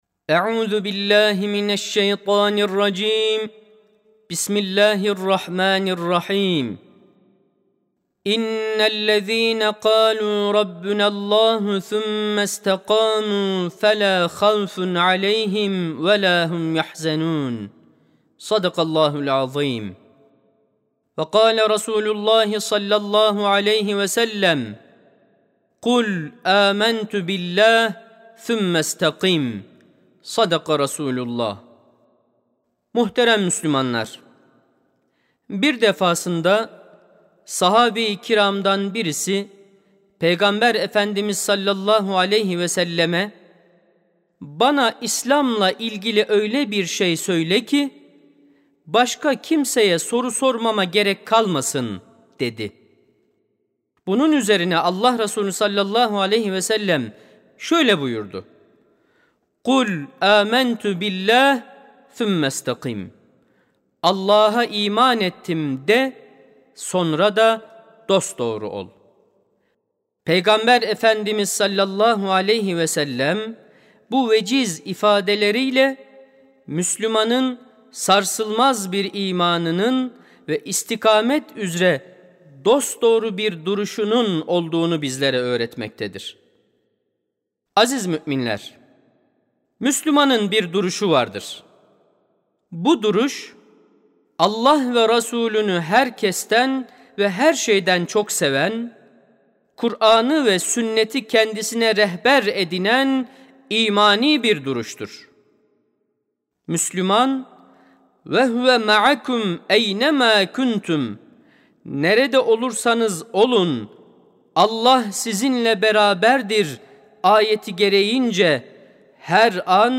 Sesli Hutbe (Müslümanın Bir Duruşu Vardır).mp3